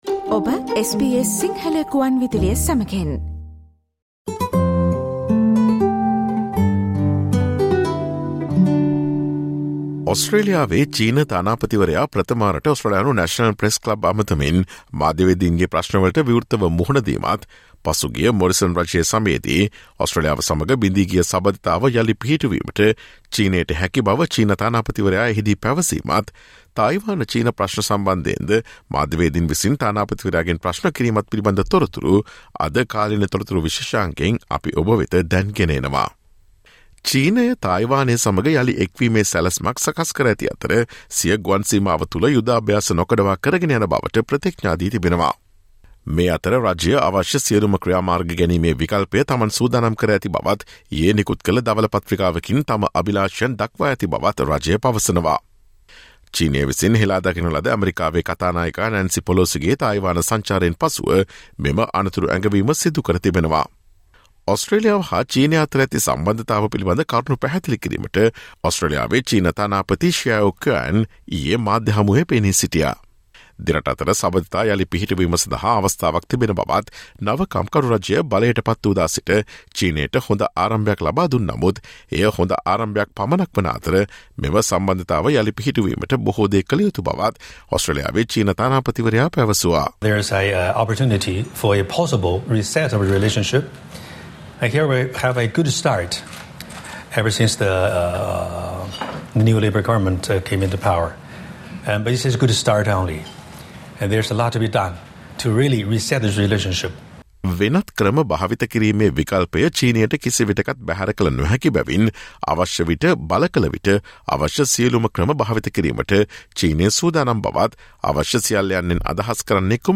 Listen to the SBS Sinhala Radio's current affairs feature broadcast on Thursday 11 August with information about the Chinese ambassador to Australia addressed the national press club for the first time yesterday (August 10) and discussed the future of China - Australian relations and China's stand on Taiwan.